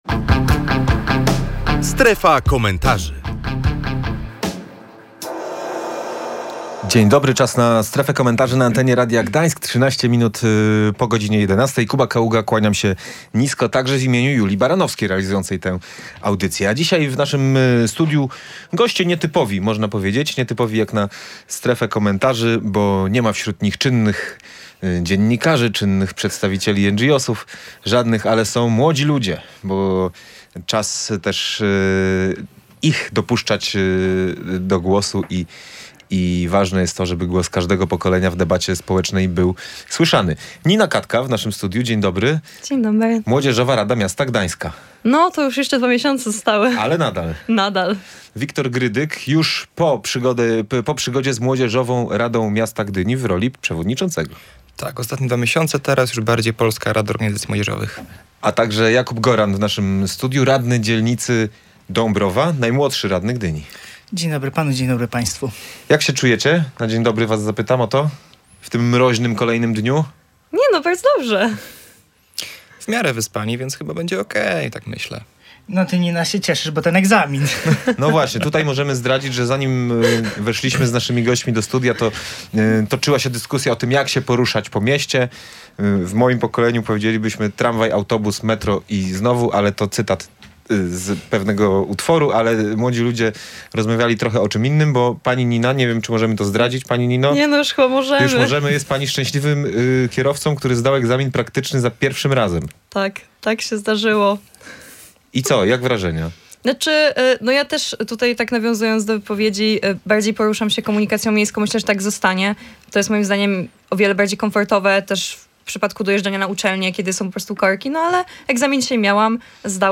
Z tego powodu w tej „Strefie Komentarzy” gościliśmy młodych działaczy.